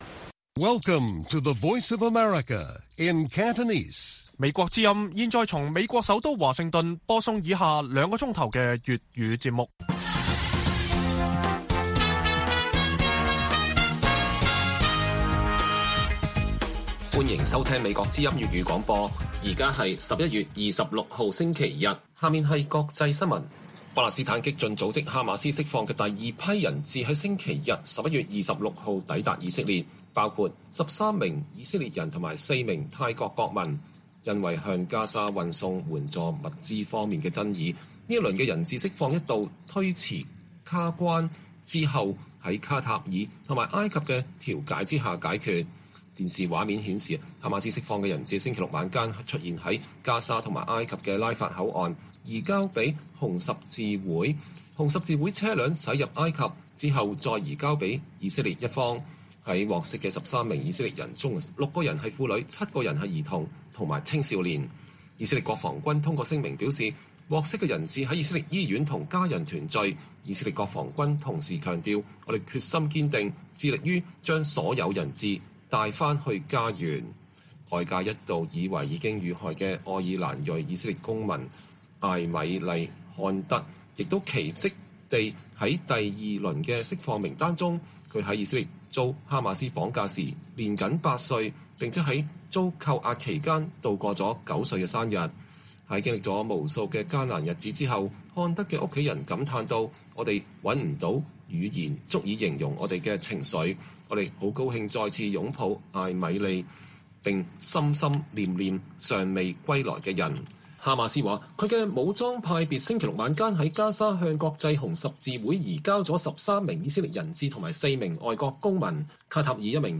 粵語新聞 晚上9-10點 : 哈馬斯釋放的第二輪17名人質抵達以色列 包括4名泰國人